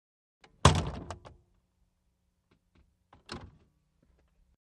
VEHICLES ASTON MONTEGO: EXT: Hood open & close.